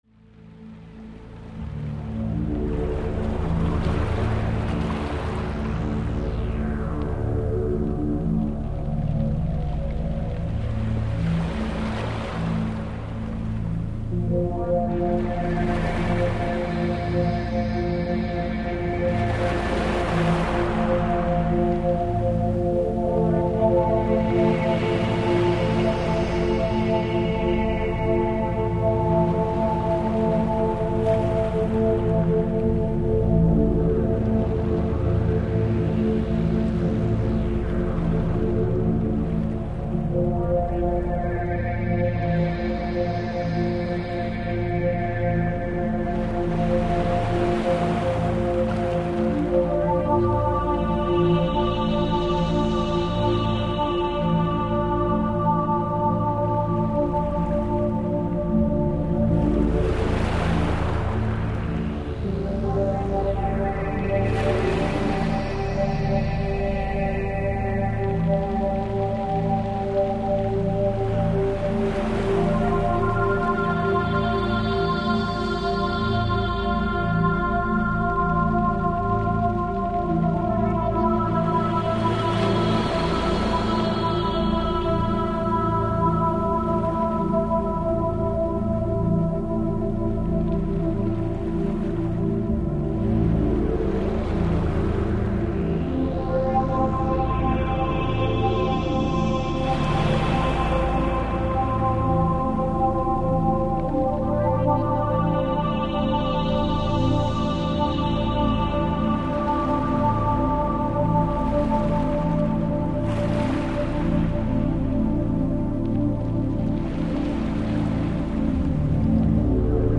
Расслабляющая музыка для детей и взрослых.